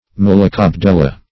Search Result for " malacobdella" : The Collaborative International Dictionary of English v.0.48: Malacobdella \Mal`a*cob*del"la\, n. [NL., fr. Gr. malako`s soft + ? a leech.]